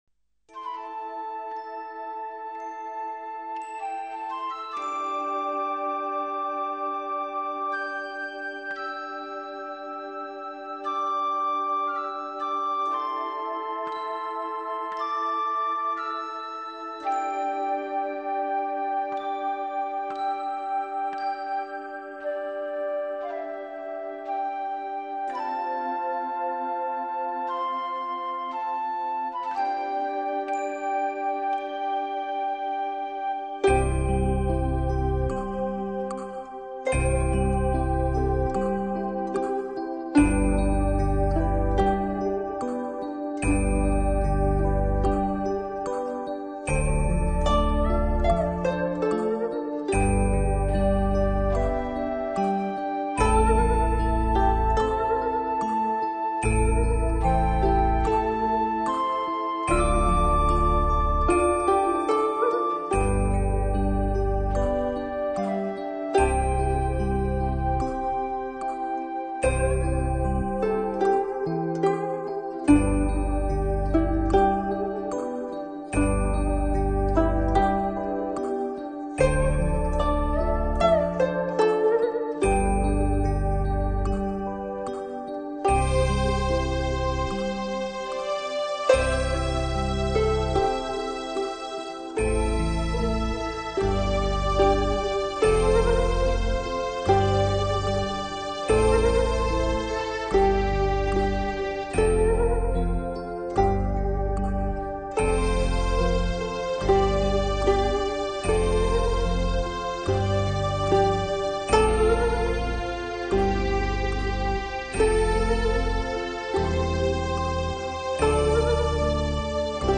最受歡迎的佛樂曲調、最傑出的古箏演奏技巧，
完全傳遞「萬物靜觀皆自得」的悠然；